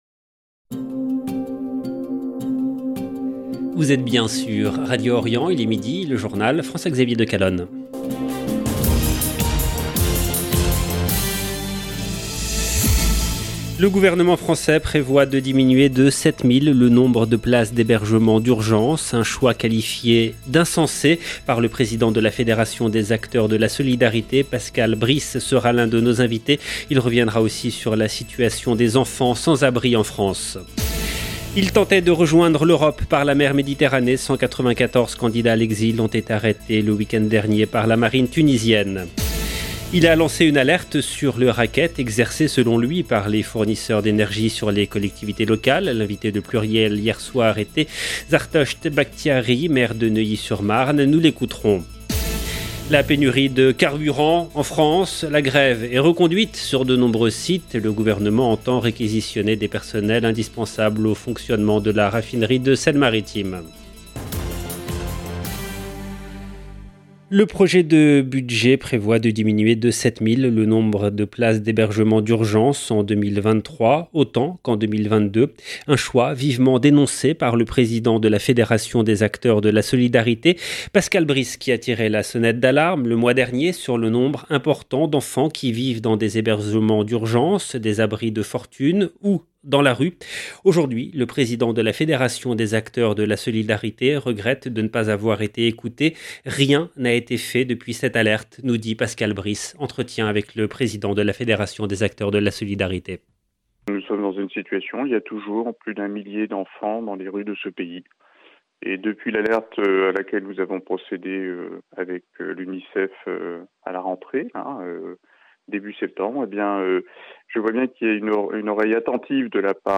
EDITION DU JOURNAL DE 12 H EN LANGUE FRANCAISE DU 12/10/2022